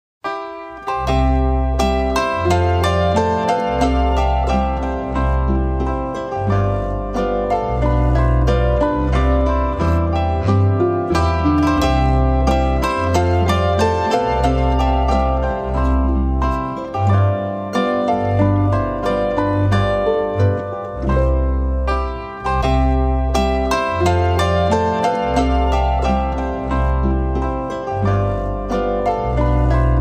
Genre: Christmas
Traditional Bavarian and Tyrolean Christmas favorites